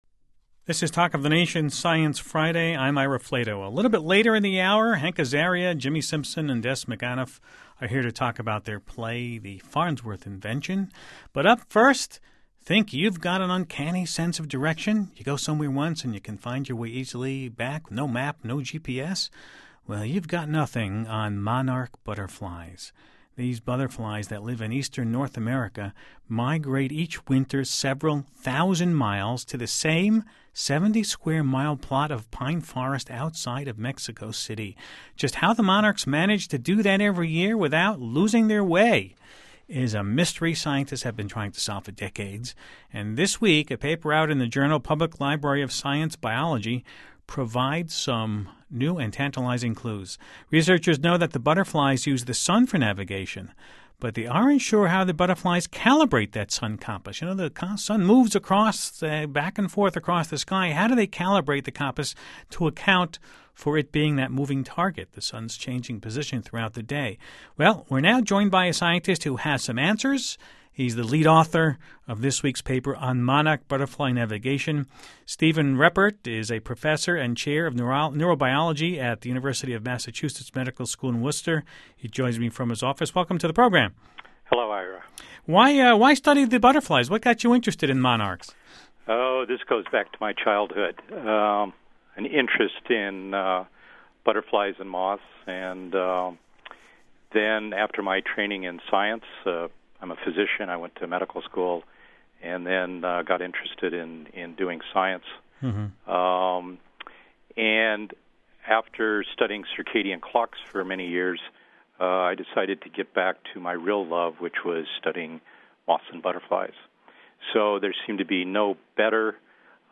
Listen to the NPR interview HERE .